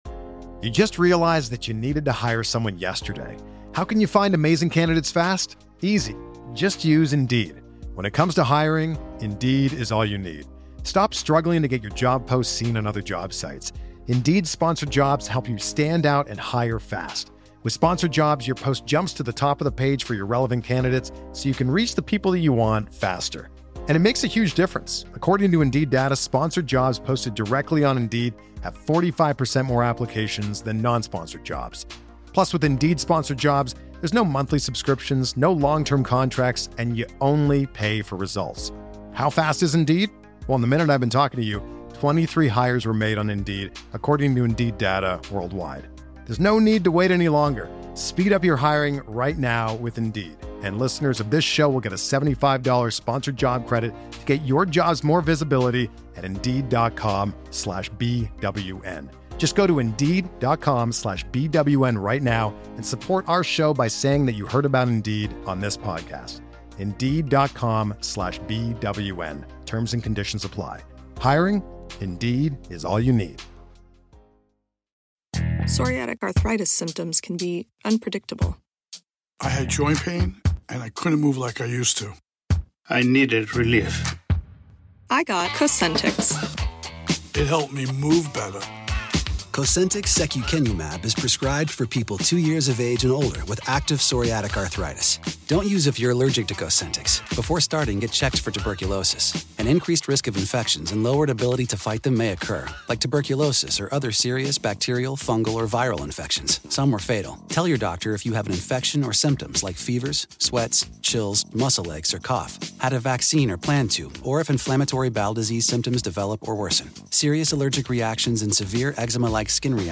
Weird Al interview from 2013